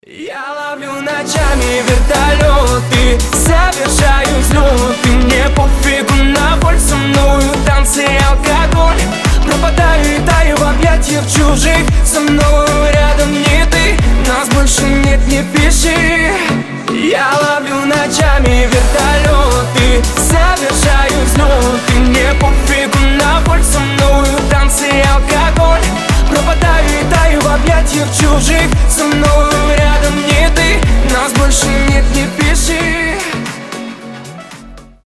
• Качество: 320, Stereo
мужской голос
громкие